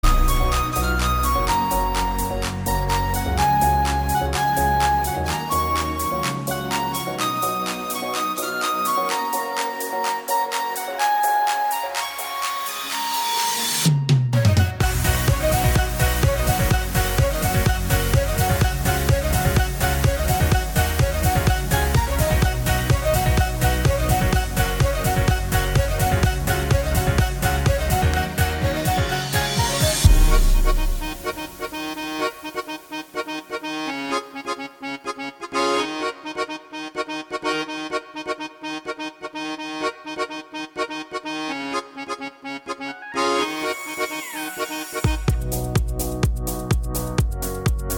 Minus All Guitars Pop (2010s) 2:55 Buy £1.50